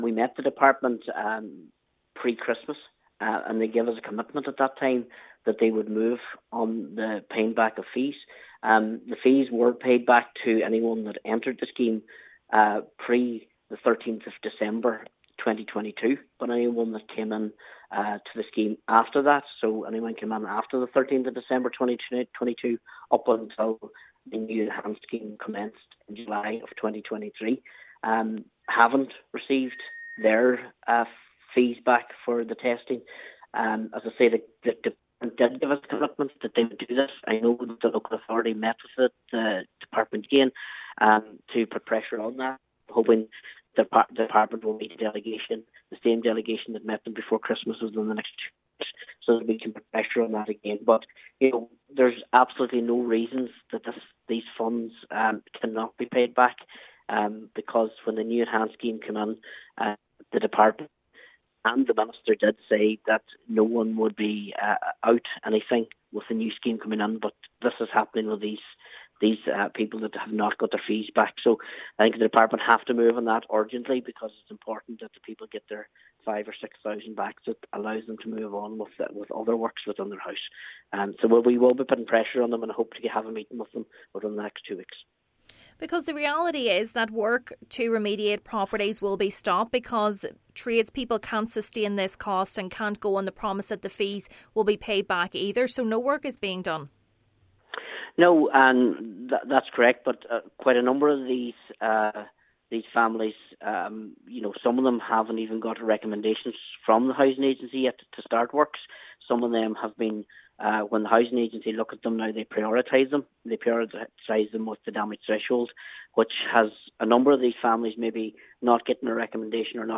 Councillor Martin McDermott however, says it’s time the Government’s honours its commitment: